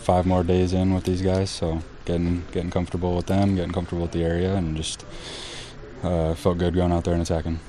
Civale said he’s getting more comfortable in the Brewers uniform.